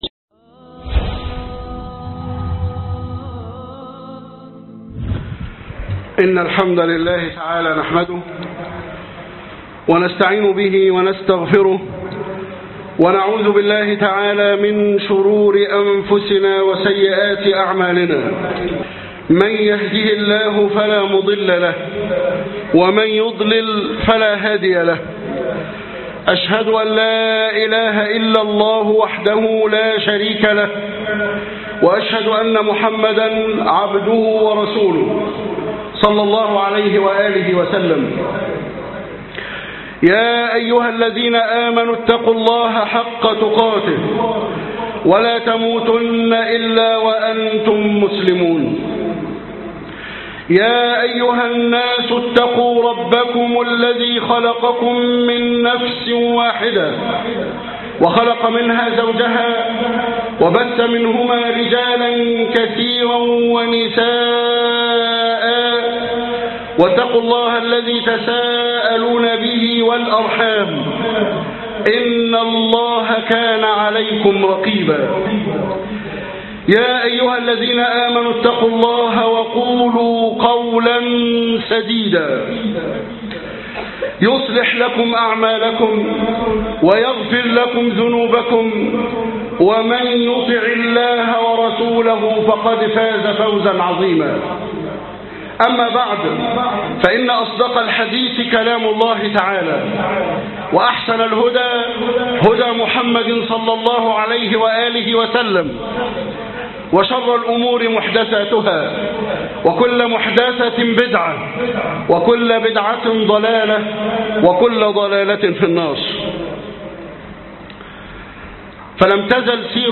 العمرة الملحمة _خطب الجمعة